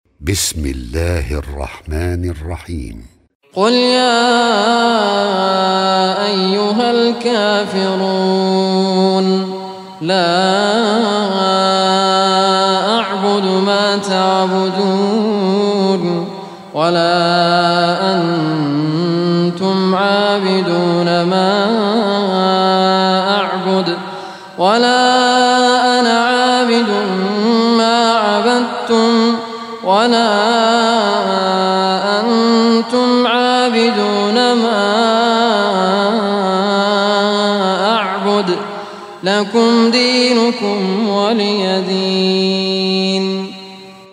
Surah Al-Kafirun Recitation by Raad Al Kurdi
Surah Al-Kafirun, listen online a very beautiful recitation in the voice of Sheikh Raad Kurdi.